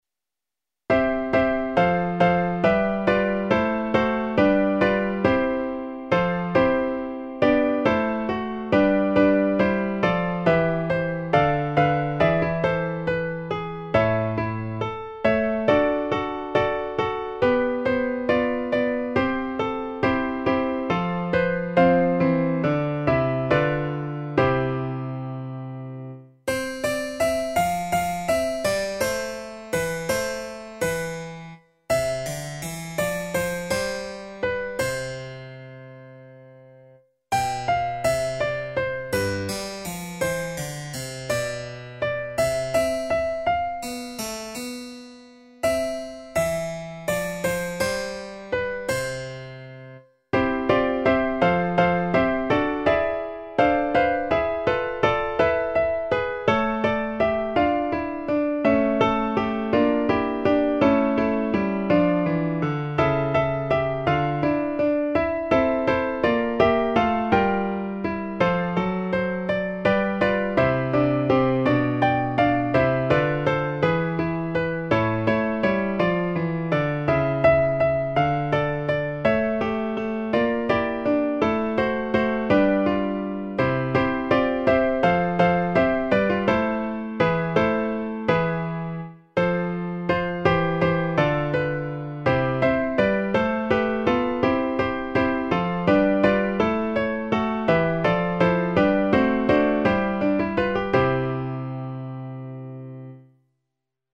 A 4 voces (Tiple I, II, Alto y Tenor)